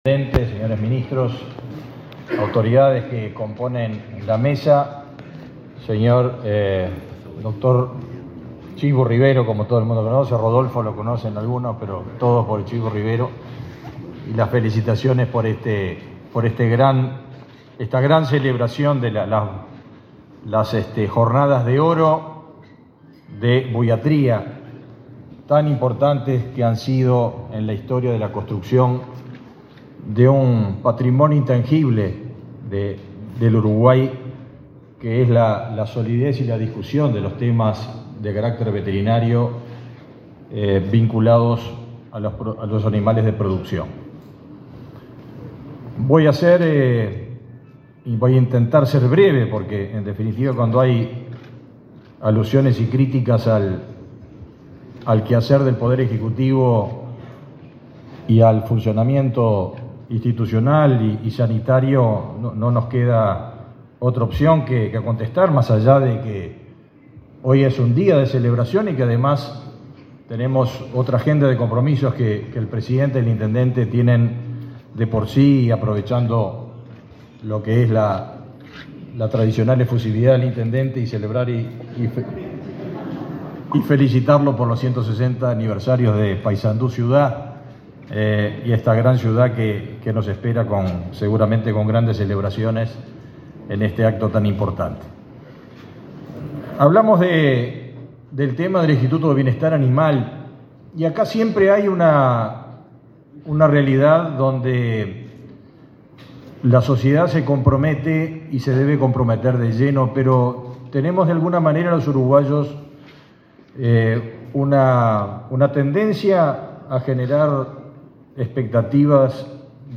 Palabras del ministro de Ganadería, Agricultura y Pesca, Fernando Mattos
El presidente de la República, Luis Lacalle Pou, participó, este 8 de junio, en el acto inaugural Jornadas Uruguayas de Buiatría.